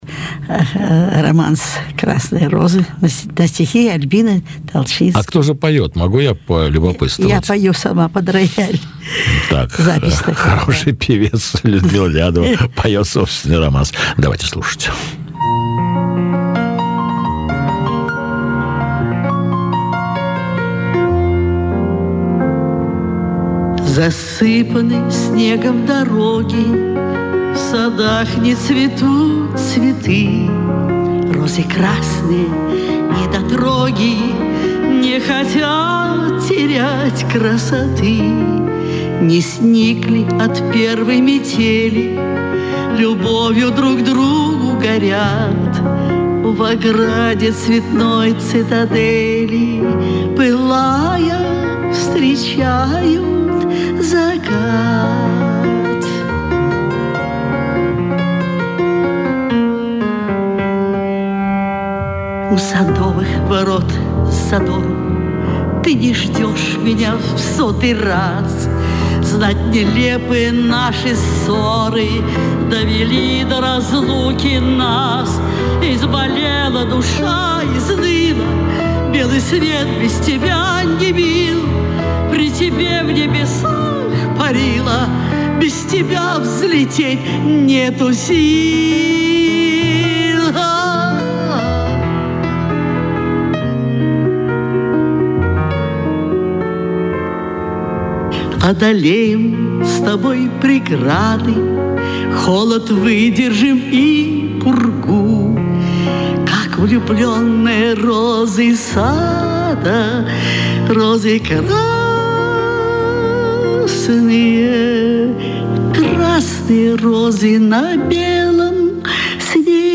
Я составил этот сборник из песен,которые прозвучали на нескольких встречах Людмилы Алексеевны с известным радиожурналистом Борисом Алексеевым и сохранил коротенькие комментарии к каждой песне...